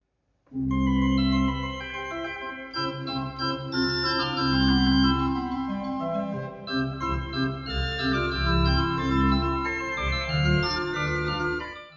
ORGAN2.WAV      Music sample reconstructed from envelopes:
organ2.wav